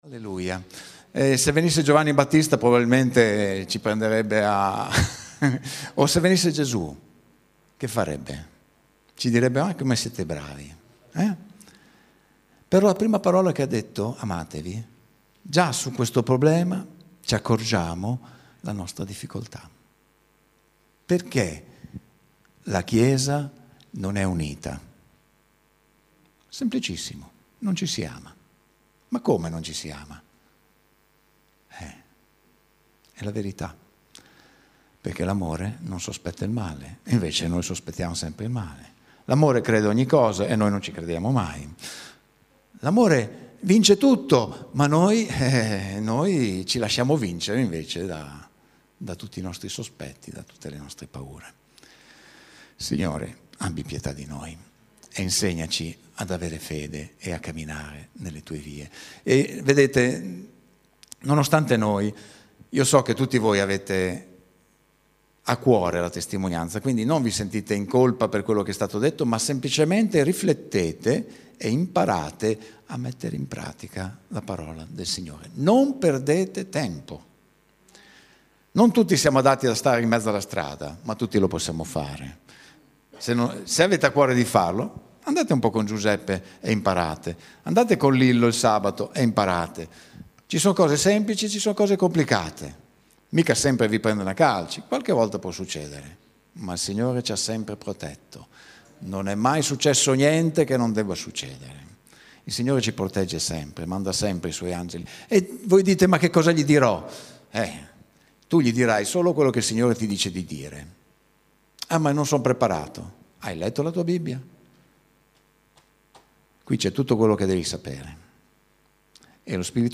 ‹ Un nuovo comandamento La chiesa in casa › Pubblicato in Messaggio domenicale